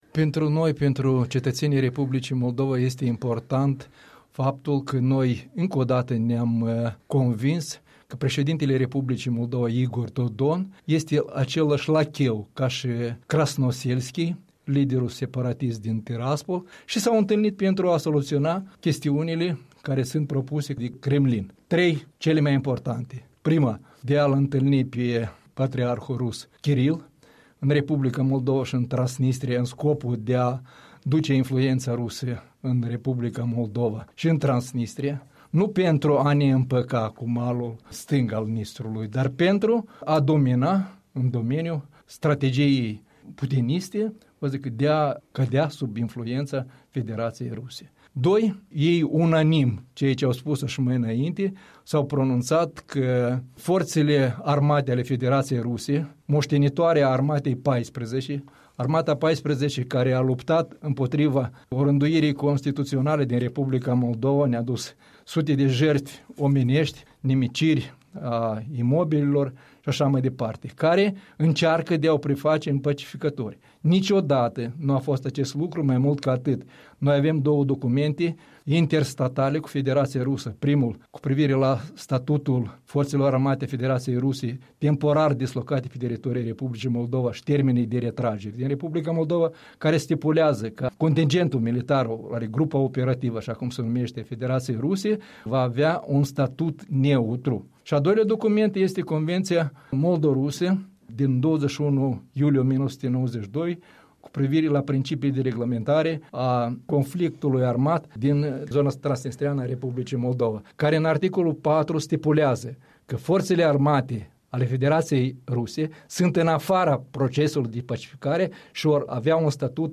Interviu cu fostul ministru de interne Victor Catan